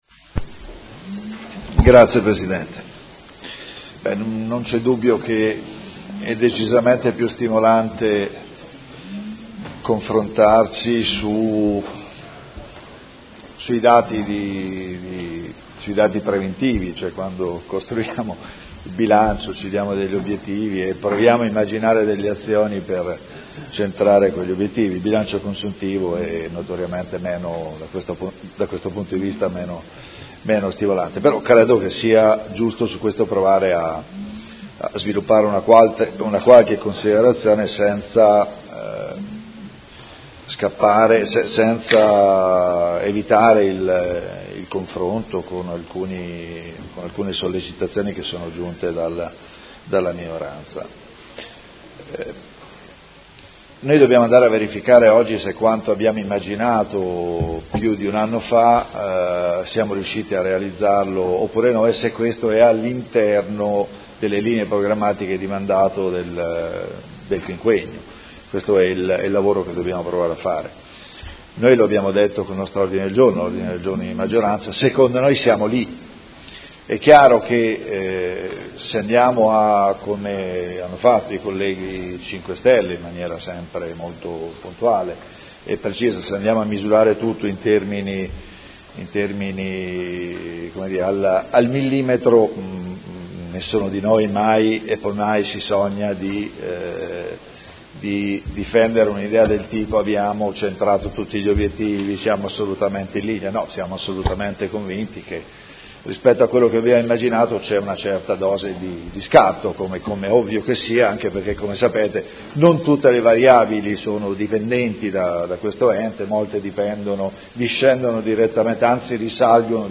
Proposta di deliberazione: Rendiconto della gestione del Comune di Modena per l’esercizio 2015 – Approvazione. Discussione